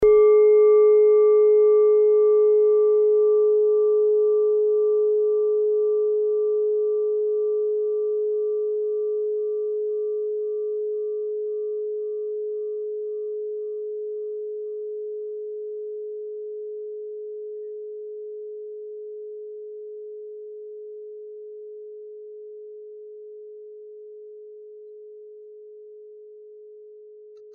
Nepal Klangschale Nr.27, Planetentonschale: Neptun
Klangschalen-Durchmesser: 15,0cm
Der Klang einer Klangschale besteht aus mehreren Teiltönen.
Die Klangschale hat bei 422.6 Hz einen Teilton mit einer
Die Klangschale hat bei 423.82 Hz einen Teilton mit einer
Signalintensität von 100 (stärkstes Signal = 100) :
klangschale-nepal-27.mp3